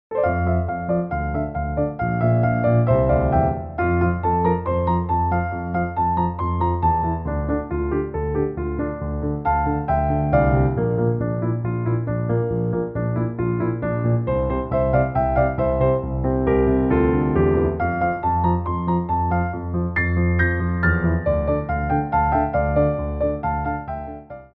Medium Allegro 2
4/4 (16x8)